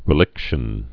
(rĭ-lĭkshən)